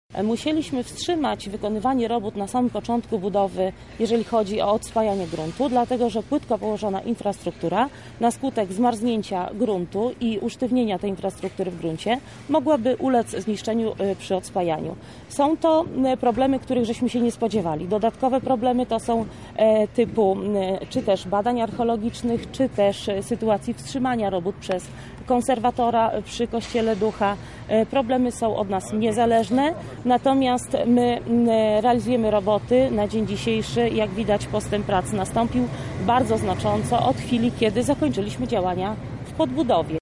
O innych problemach na jakie się natknięto mówi